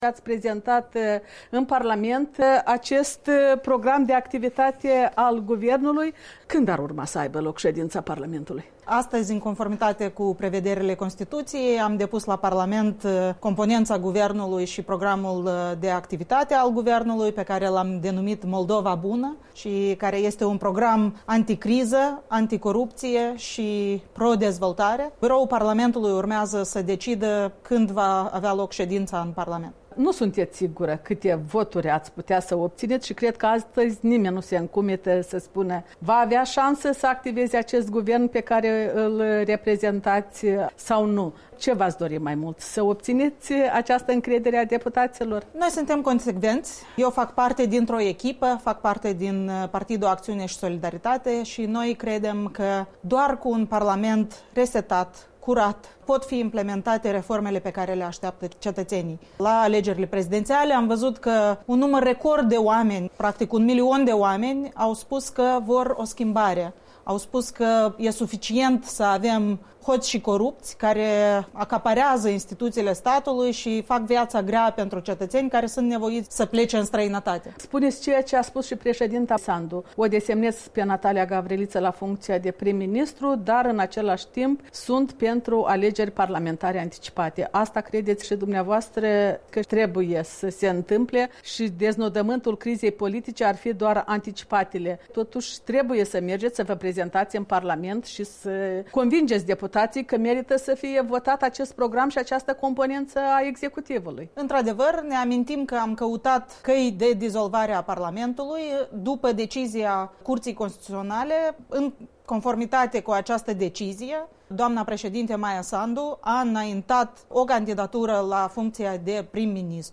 Interviu cu Natalia Gavriliță